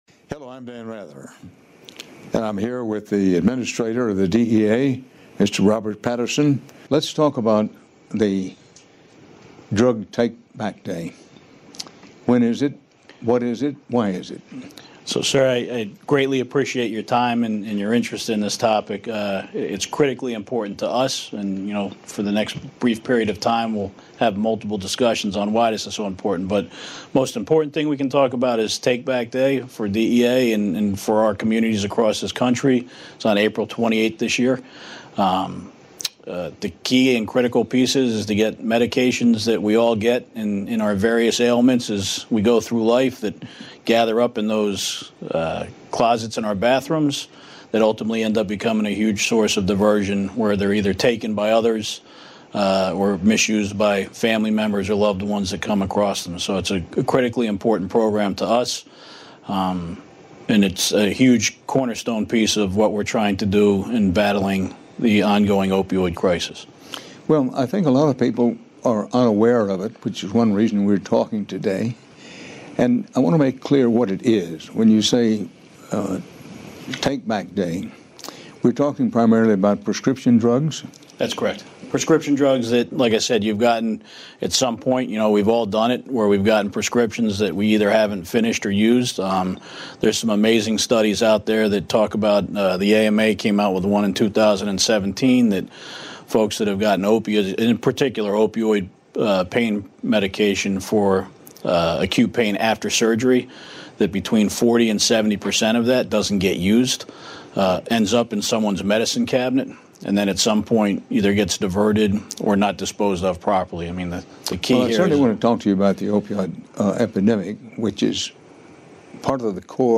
In this podcast, Dan Rather sits down with the U.S. Drug Enforcement Administration Administrator, Robert Patterson, to discuss the nation’s drug epidemic. They cover alarming statistics about prescription drug misuse and overdose death, as well as National Prescription Drug Take Back Day – happening April 28.